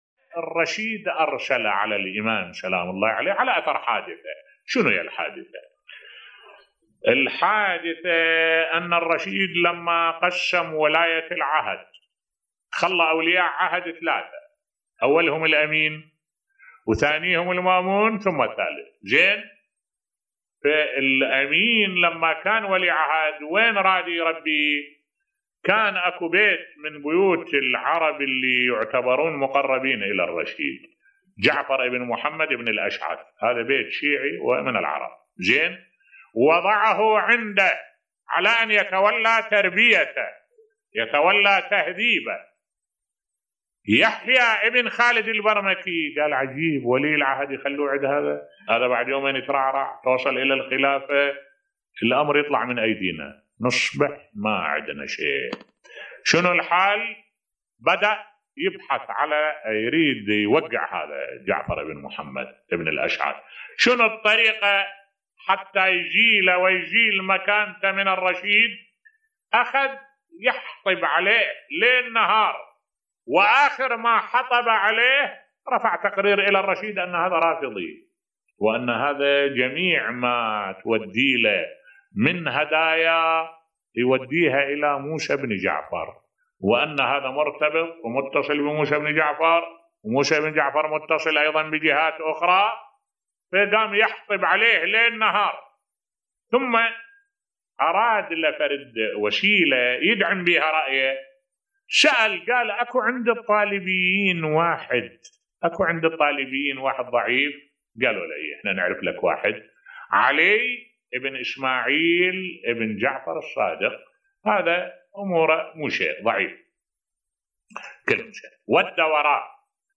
ملف صوتی سبب اعتقال الإمام موسى بن جعفر عليه السلام بصوت الشيخ الدكتور أحمد الوائلي